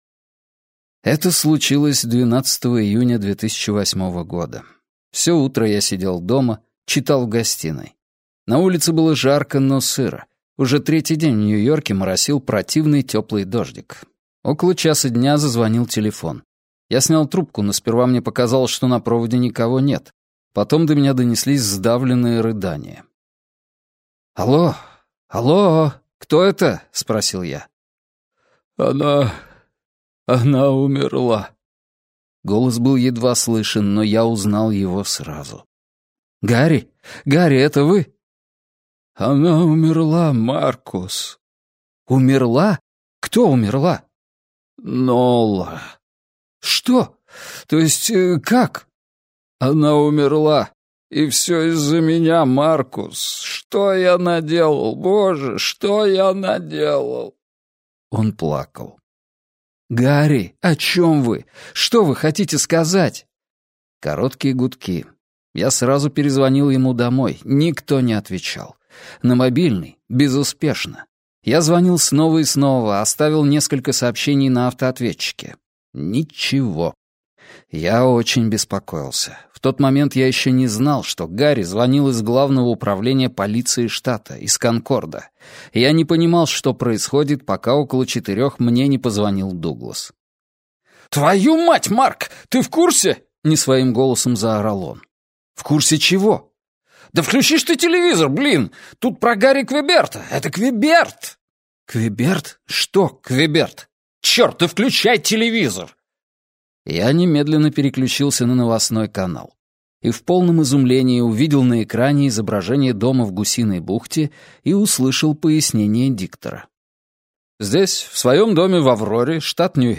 Аудиокнига Правда о деле Гарри Квеберта - купить, скачать и слушать онлайн | КнигоПоиск